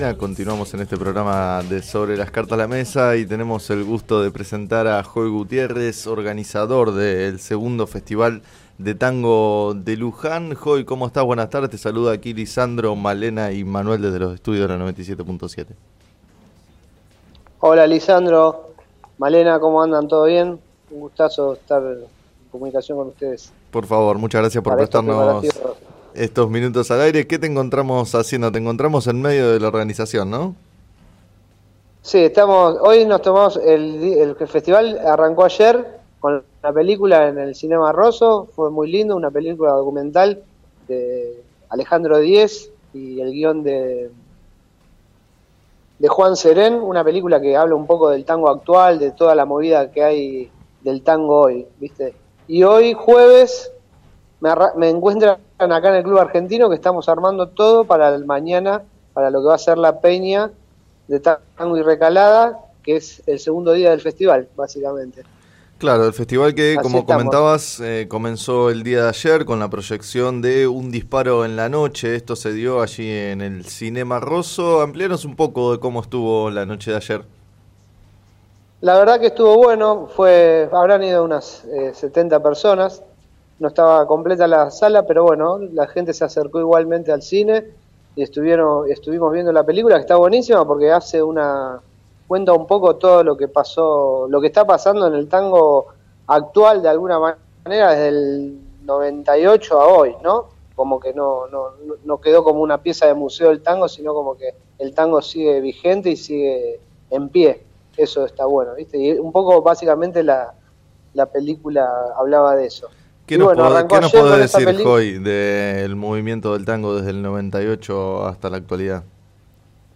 Entrevistado en el programa “Sobre las cartas la mesa” de FM Líder 97.7